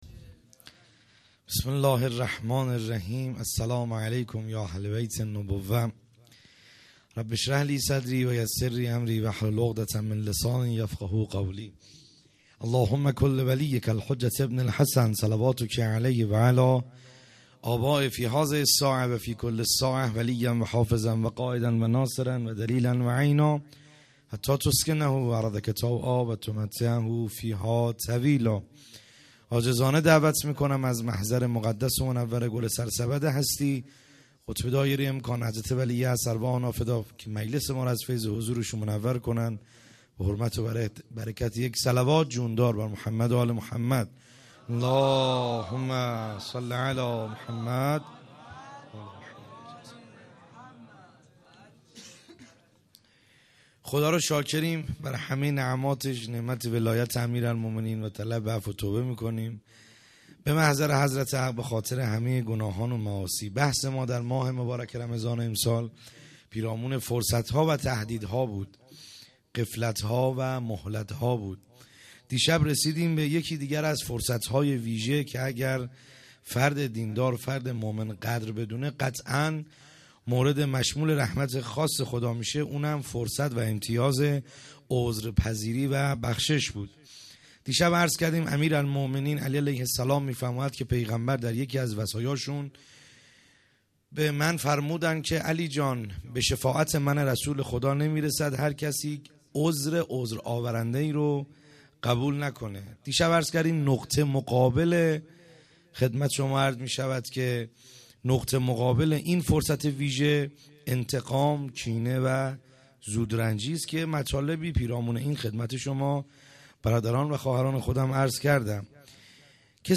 خیمه گاه - بیرق معظم محبین حضرت صاحب الزمان(عج) - سخنرانی | شب ششم